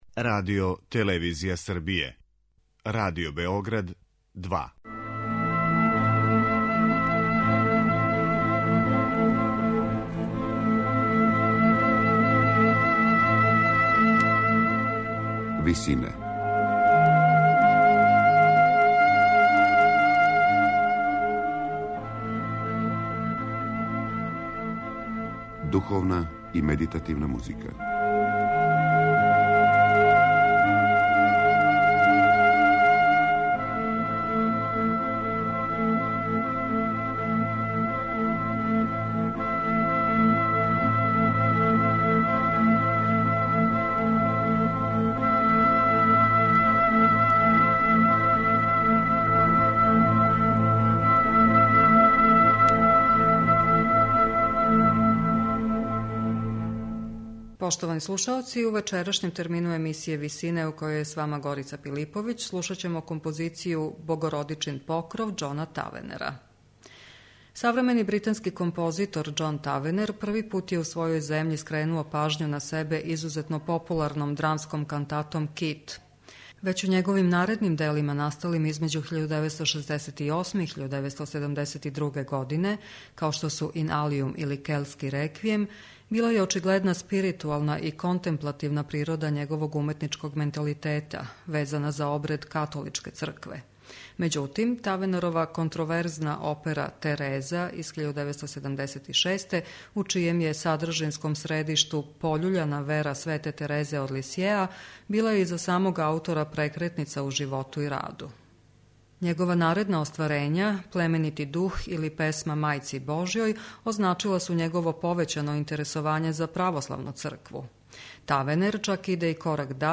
за виолончело и гудаче.
медитативне и духовне композиције